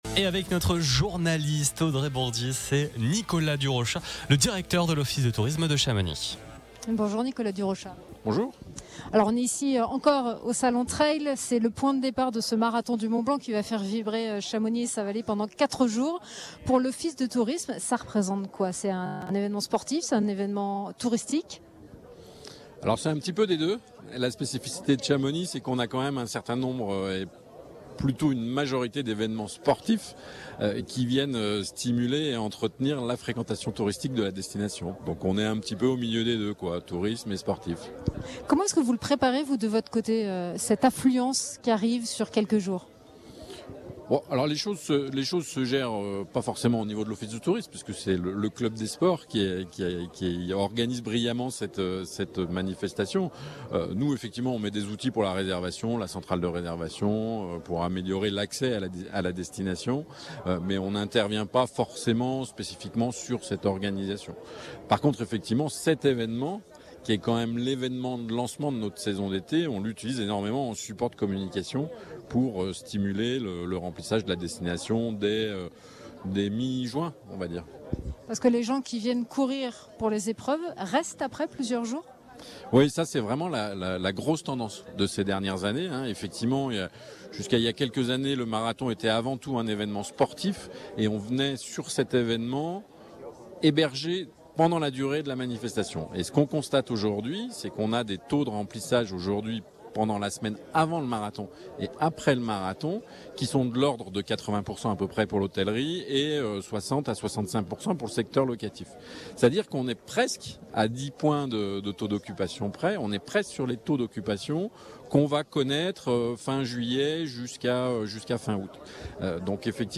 Duplex depuis le village exposants Place du Mont-Blanc
Ce jeudi après-midi, nous étions en direct en duplex depuis le village des exposants, pour une émission spéciale consacrée à cet événement sportif.